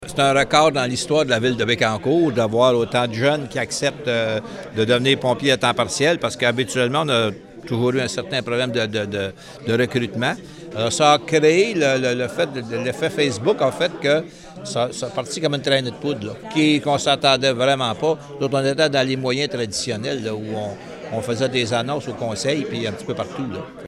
Voici le maire Jean-Guy Dubois :